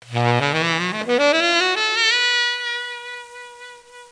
horn9.mp3